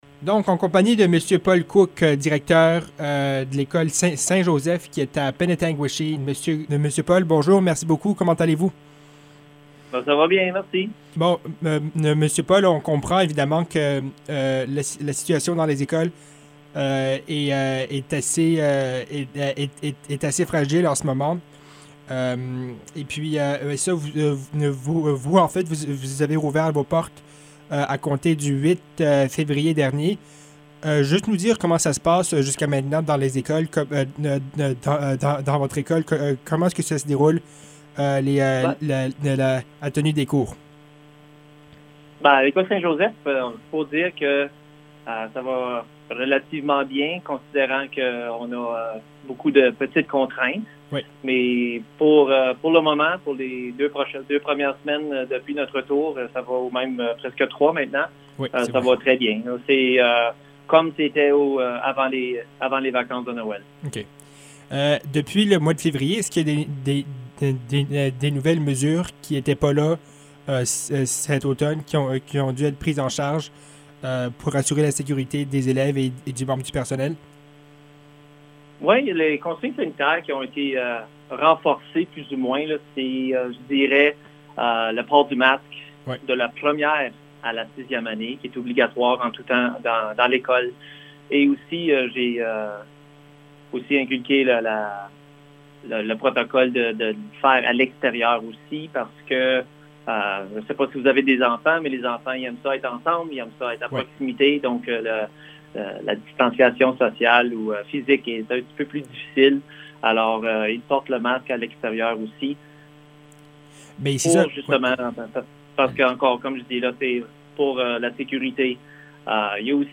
entrevue-Viamonde.mp3